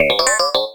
combatdrone.ogg